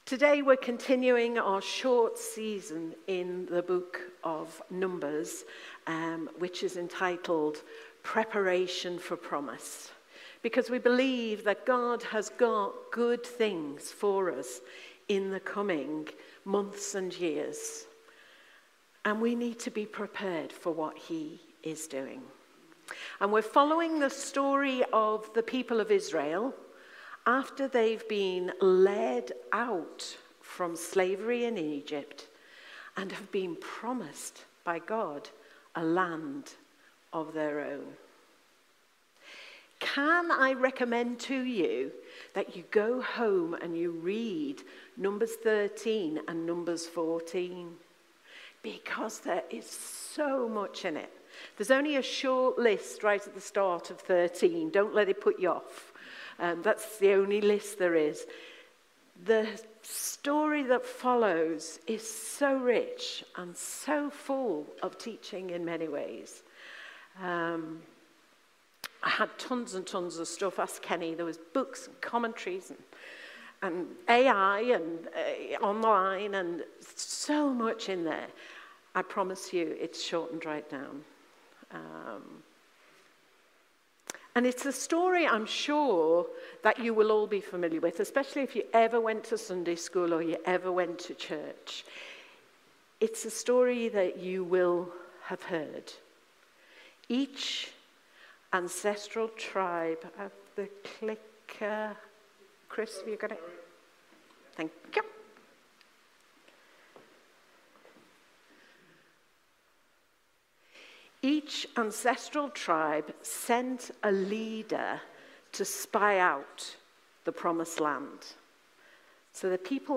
Passage: Numbers 14:1-12,Numbers 14:31-45 Service Type: Sunday Morning